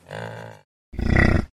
Звуки яка: два разных звука, которые издает як